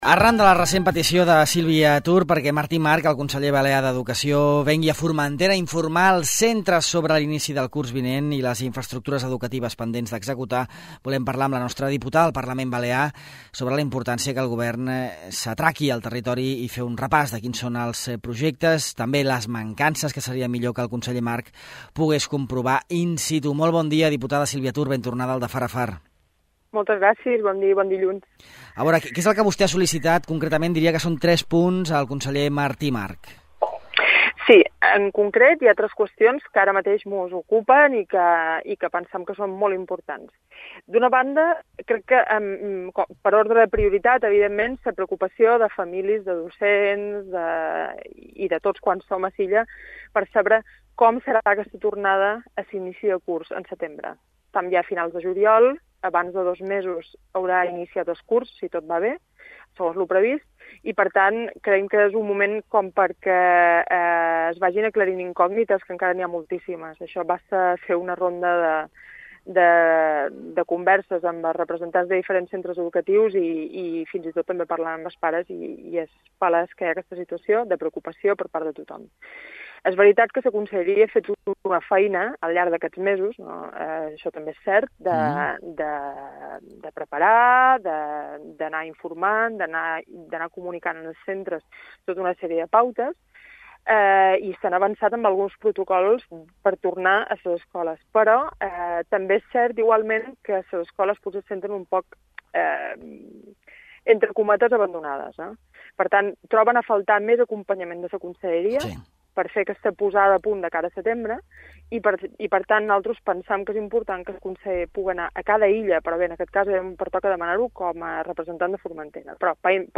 Arran de la recent petició de Sílvia Tur perquè Martí March, conseller balear d’Educació, vingui a Formentera a informar els centres sobre l’inici del curs vinent i les infraestructures educatives pendents d’executar, al De Far a Far parlam amb la nostra diputada al Parlament sobre la importància que el Govern s’apropi al territori i fem un repàs de quins són els projectes, i també les mancances, que seria millor que el conseller March pogués comprovar in situ.